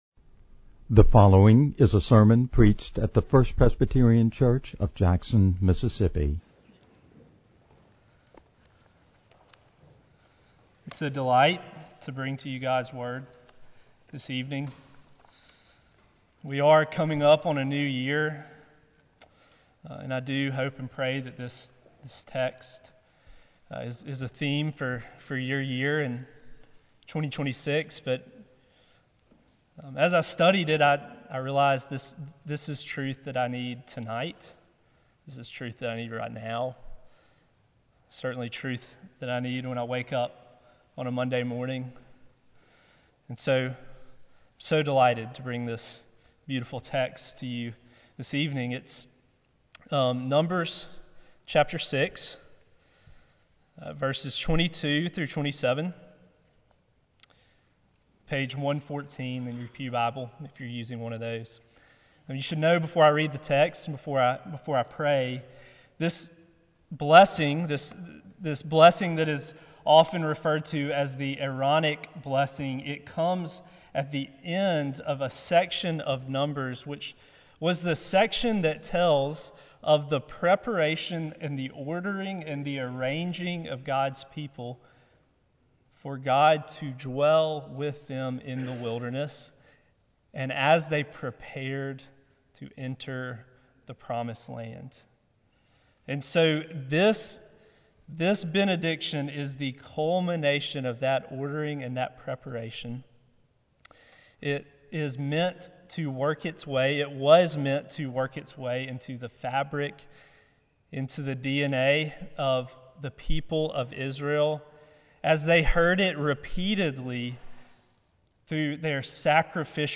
No attempt has been made, however, to alter the basic extemporaneous delivery style, or to produce a grammatically accurate, publication-ready manuscript conforming to an established style template.